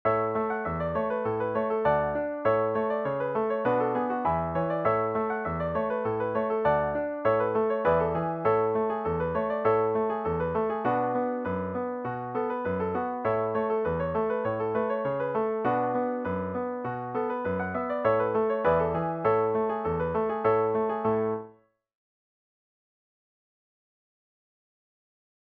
DIGITAL SHEET MUSIC - PIANO ACCORDION SOLO